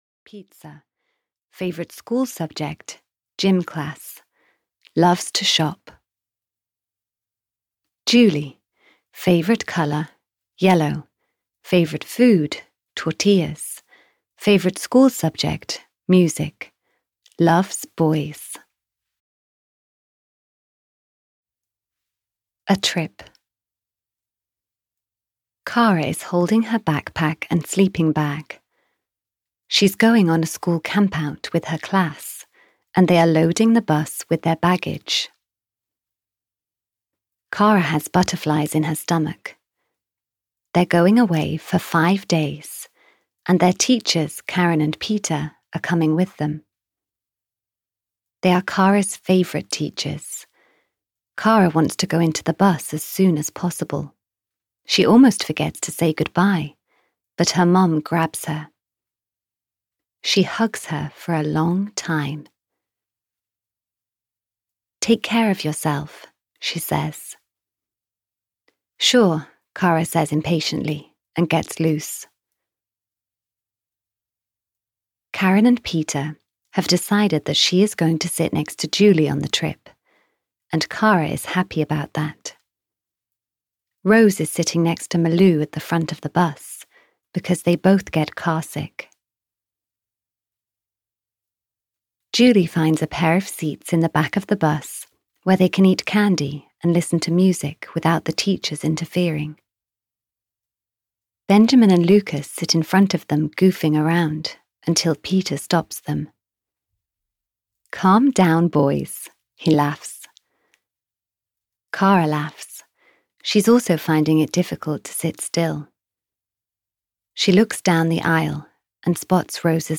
K for Kara 9 - The School Camp (EN) audiokniha
Ukázka z knihy